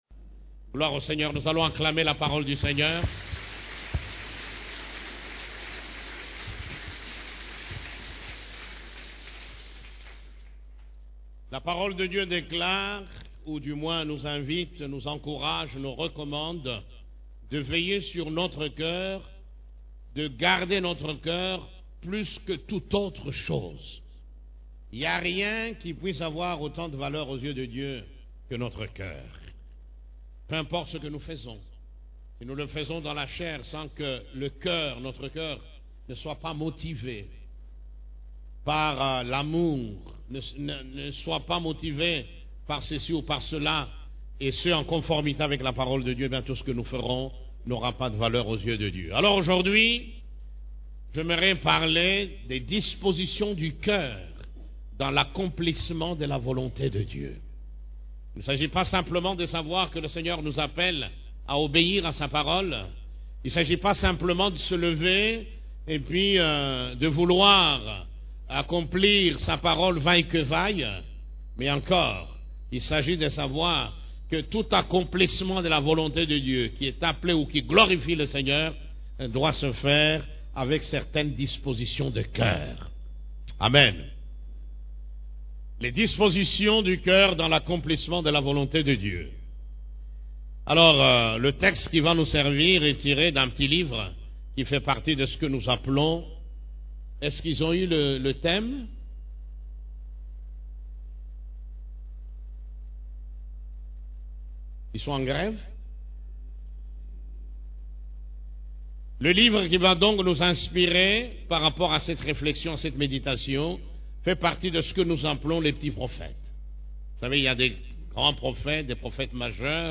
CEF la Borne, Culte du Dimanche, Les dispositions du coeur dans l'accomplissement de la volonté de Dieu